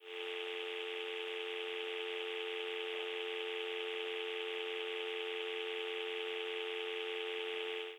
Descarga de Sonidos mp3 Gratis: tono telefono.